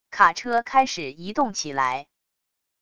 卡车开始移动起来wav音频